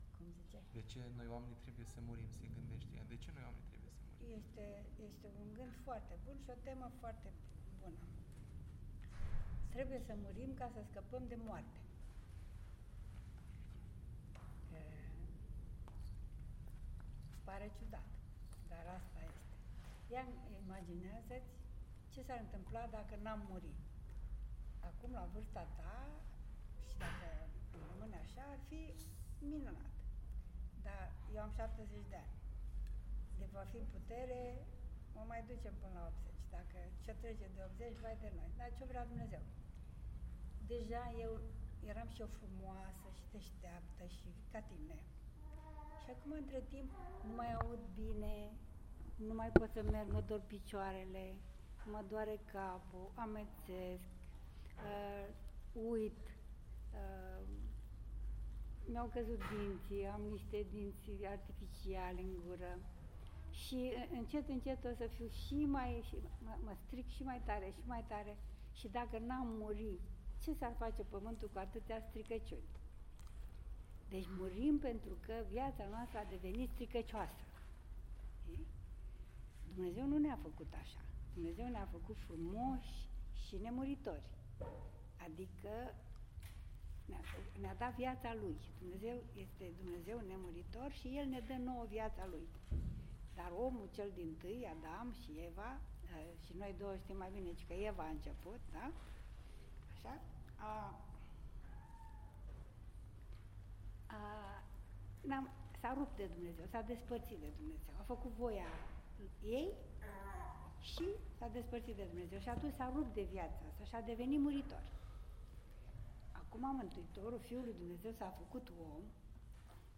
Cuvânt
în Parohia Luton